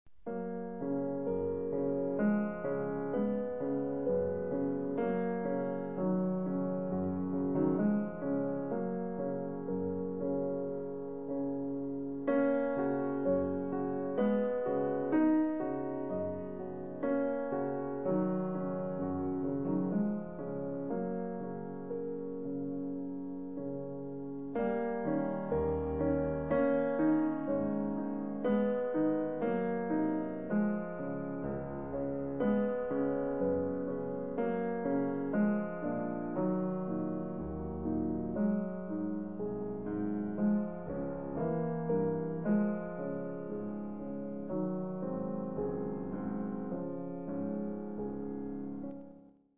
interprète Schumann
Ce qui surprend en premier lieu, c'est le tempo adopté pour l'exécution des oeuvres.
Le tempo lent, ou modéré est de rigueur, et évidemment lorsqu'on connaît les oeuvres pour les avoir entendu par d'autres pianistes, on a tendance à bouillir d'impatience et à presser le mouvement.
Le tempo est lent, mais l'atmosphère qui se dégage de cette interprétation est tout à fait poignante, rempli d'une sérénité devant laquelle on ne peut rester insensible.